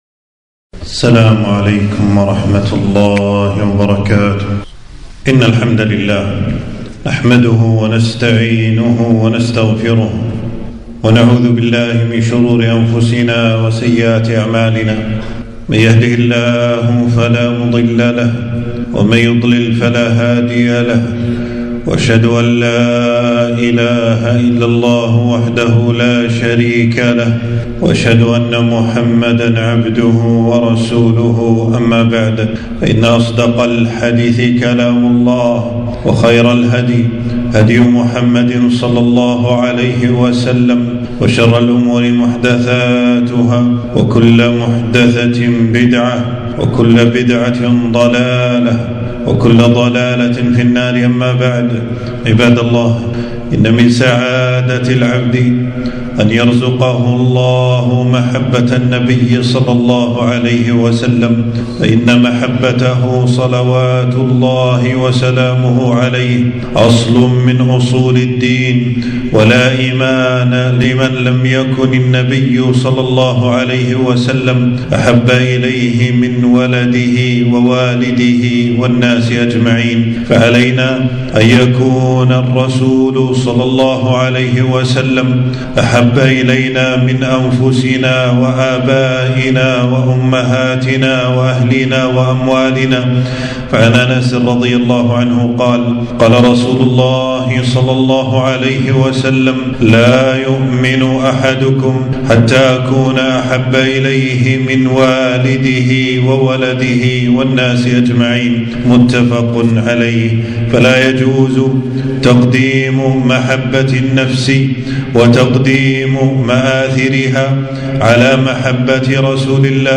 خطبة - التحذير من الغلو في النبي صلى الله عليه وسلم وبدعية الاحتفال بمولده